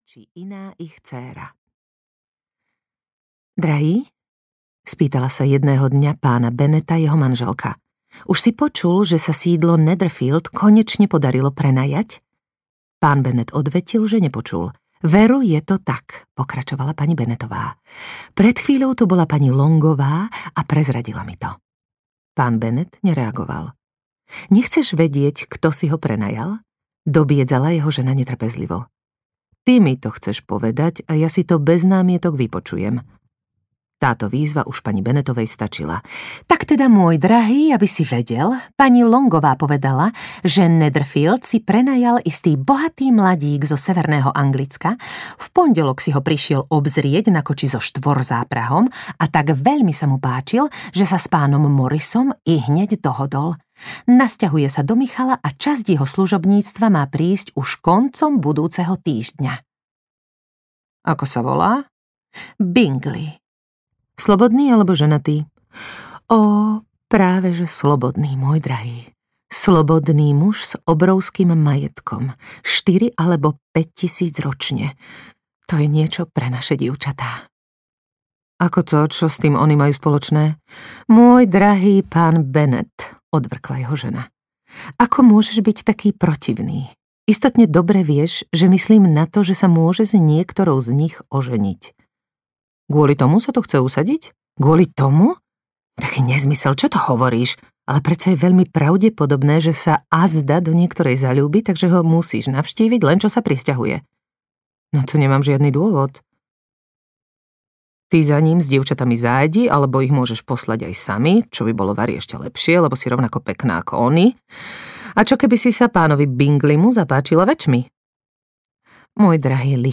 Pýcha a predsudok audiokniha
Ukázka z knihy
• InterpretHelena Krajčiová